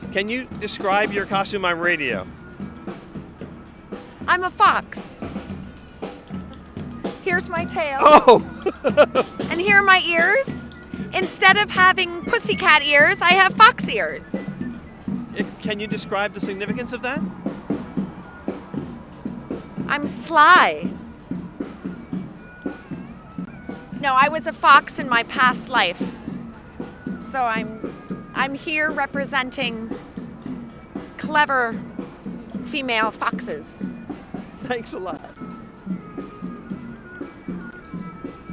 DISCUSSES WITH ONE MARCHER HER FOX COSTUME.